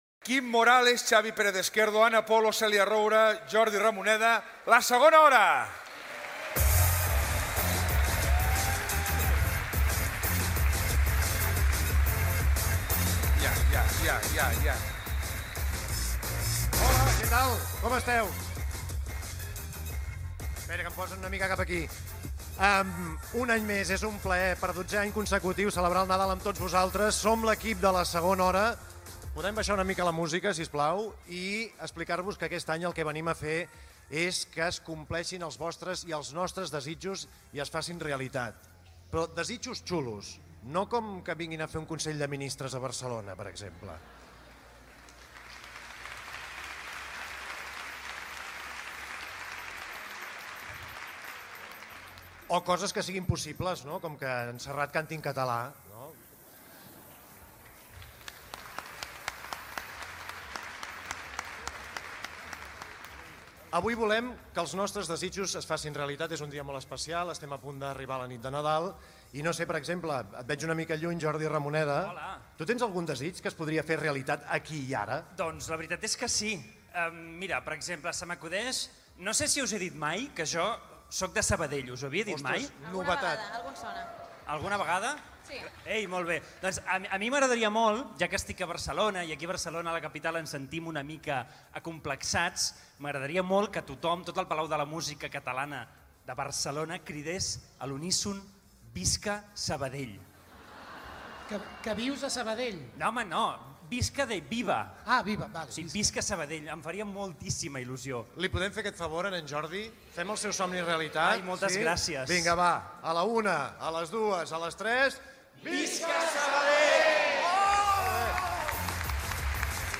Especial “És Nadal al Món 2018” fet al Palau de la Música Catalana.
Info-entreteniment